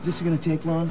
Sound bytes were captured from the vh-1 Special of Leif Garrett:  Behind The  Music and Where Are They Now.